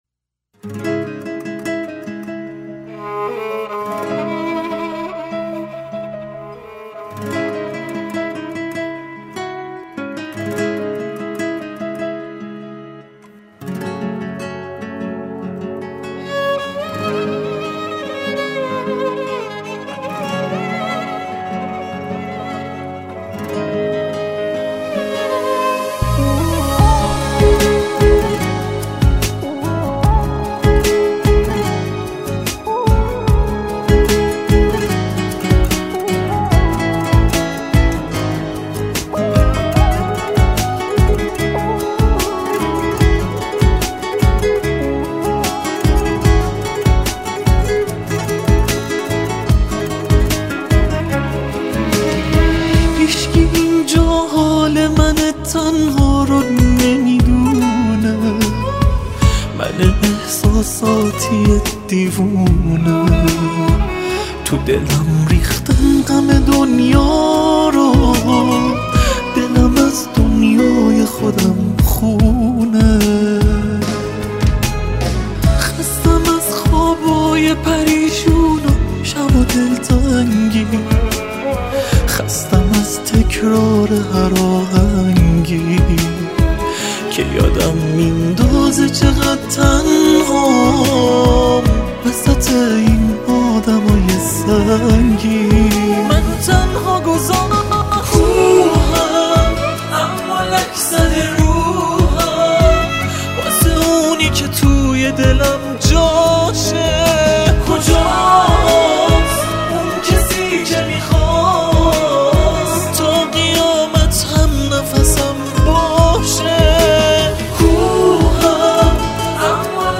گیتار